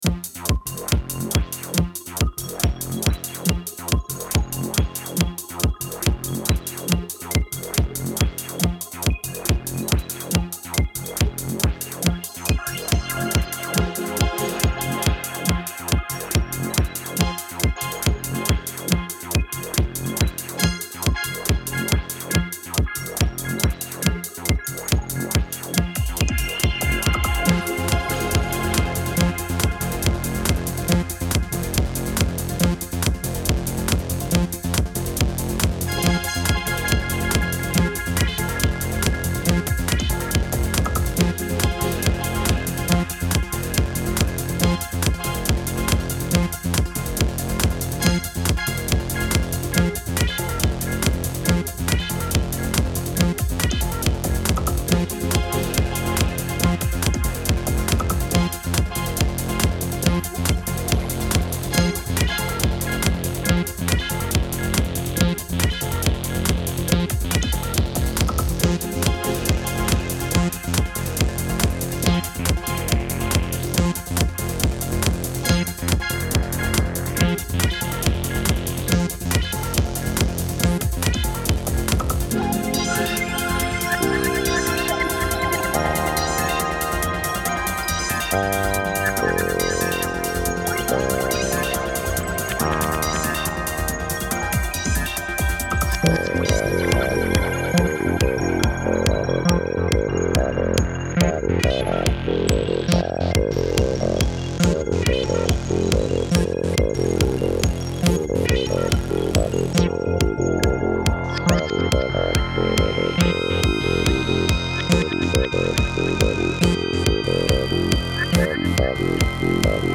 electronica, techno, psychedelic